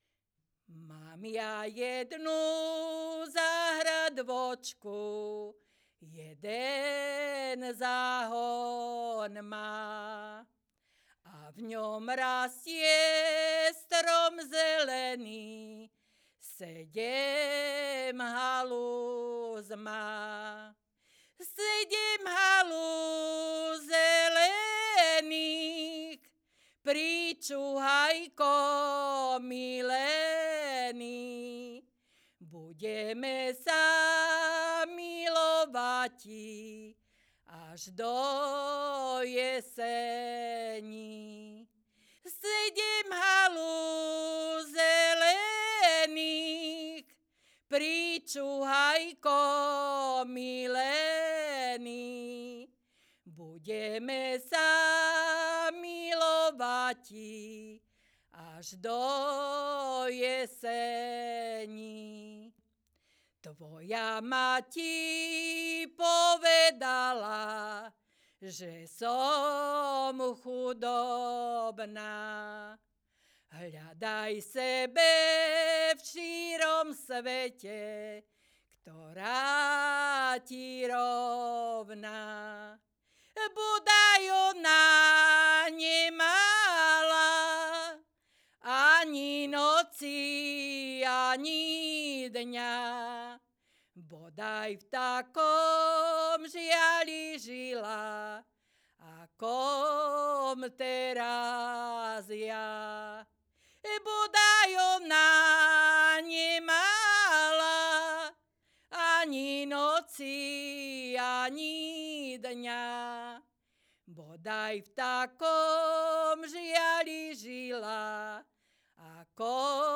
Popis sólo ženský spev bez hudobného sprievodu
Miesto záznamu Brehy
Kľúčové slová ľudová pieseň
valčík